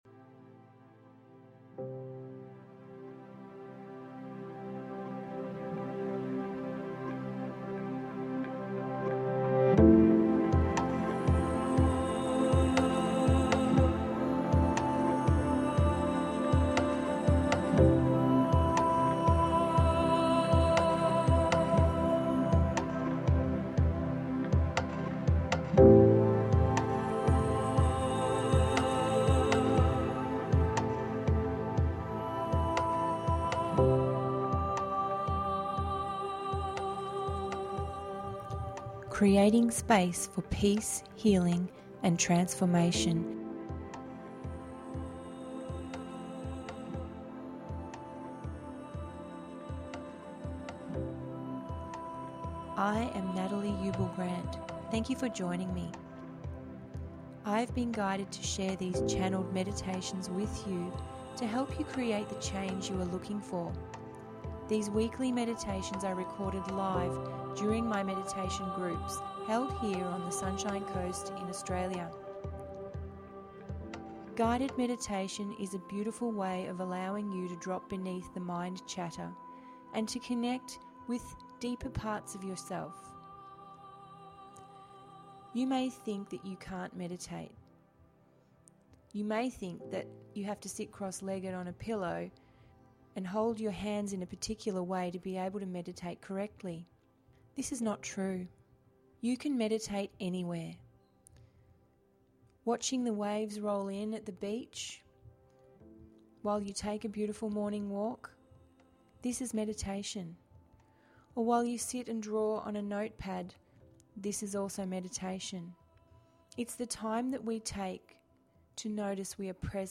Guided Meditation duration approx. 22 mins